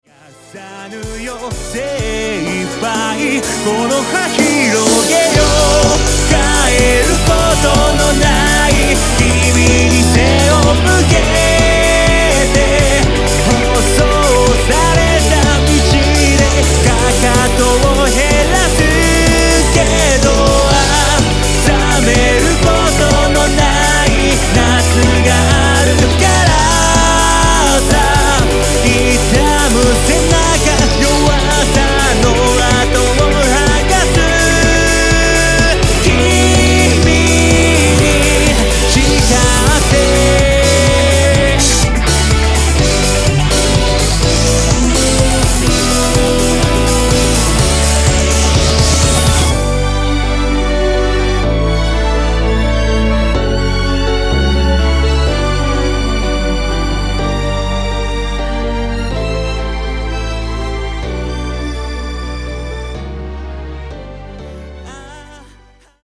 クロスフェードデモ